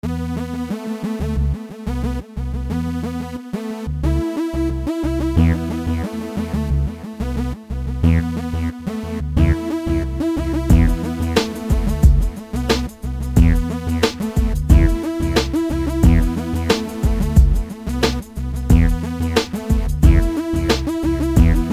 Минуса рэп исполнителей